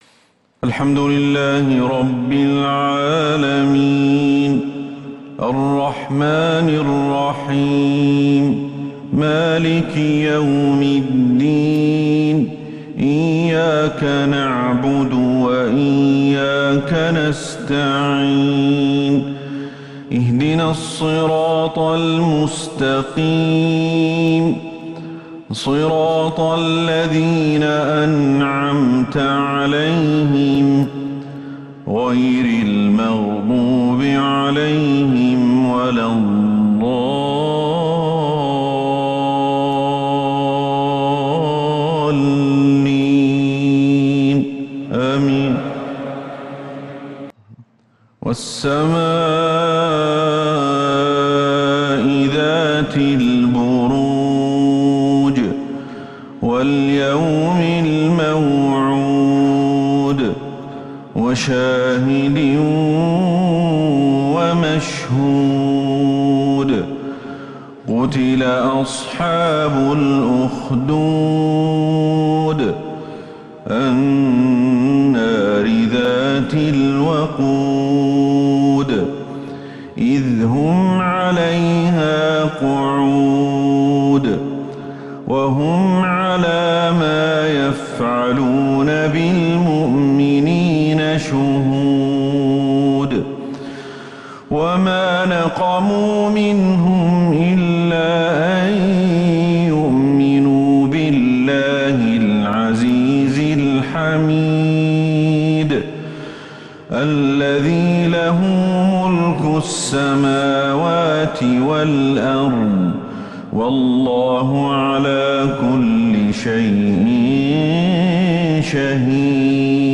تلاوهـ حجازية أيوبية سورة {البروج} الأربعاء 23 ذو القعدة 1443هـ > 1443 هـ > الفروض